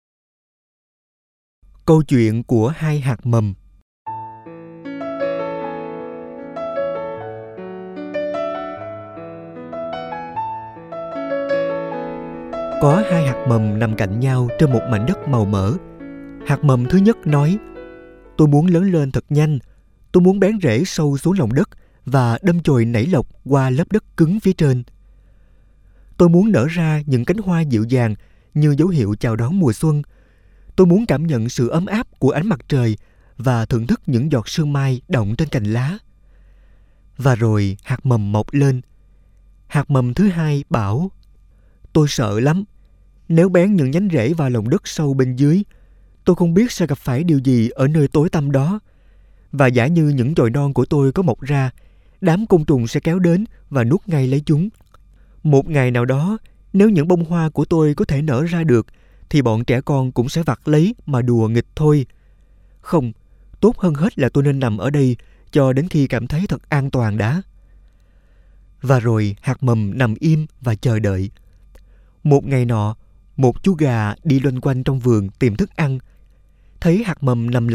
Sách nói Hạt Giống Tâm Hồn Tập 3 - Từ Những Điều Bình Dị - Sách Nói Online Hay